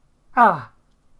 æ
æ-individual.mp3